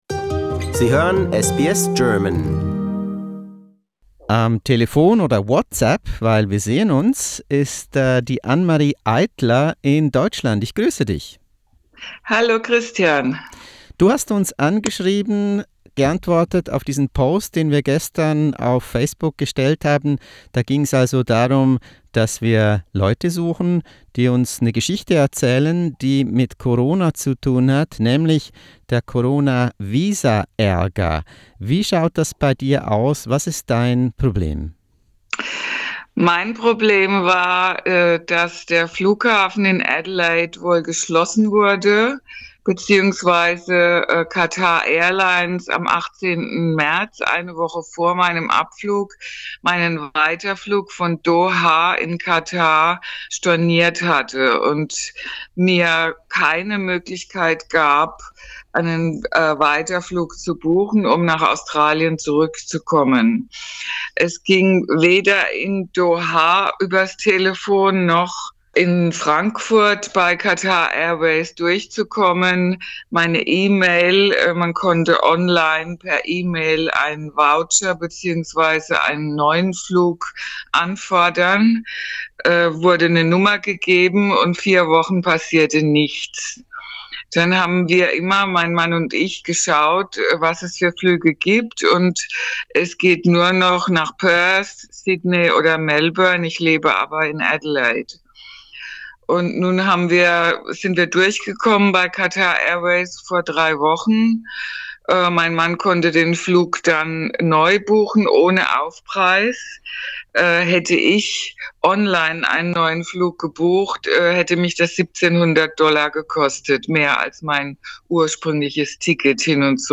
Sie erzählt uns im Interview ihren Fall, und was sie vermisst, ausser ihren Mann und die 19-jährige Tochter.